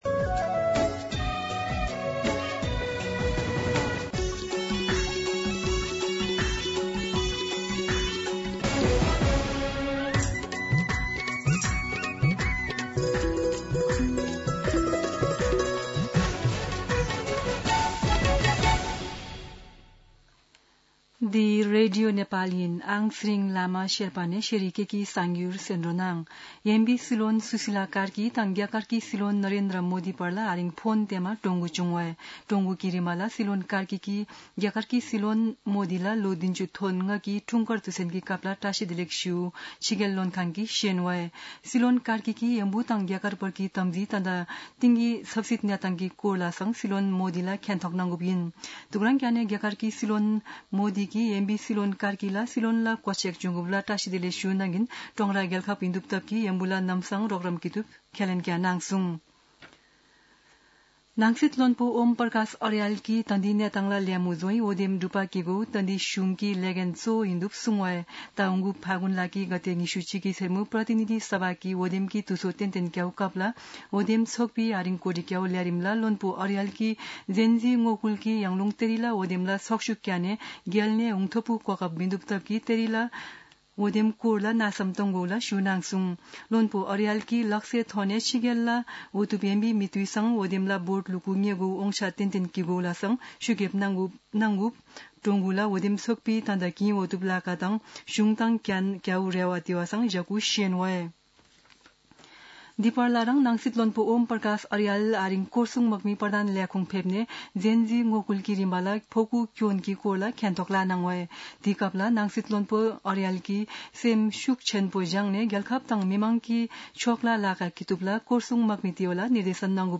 शेर्पा भाषाको समाचार : २ असोज , २०८२